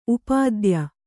♪ upādya